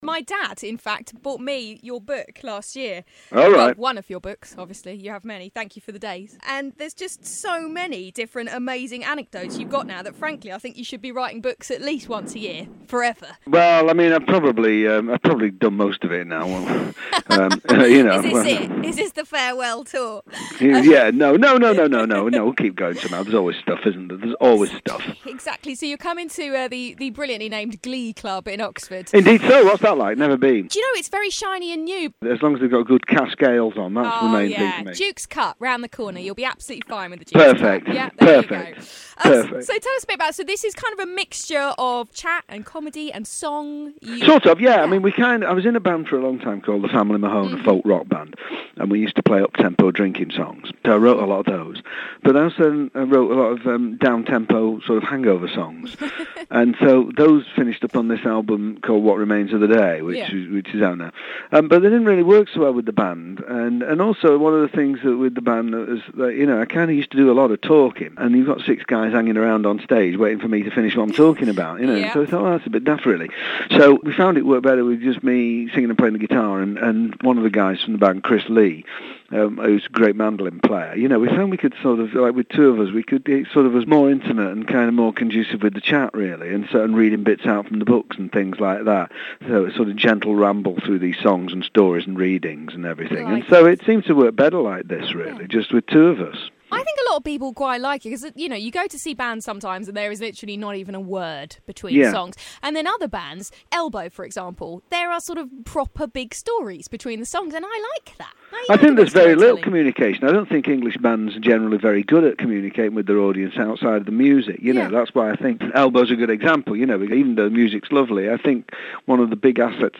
Glide's Morning Glory Interview with Mark Radcliffe Part 1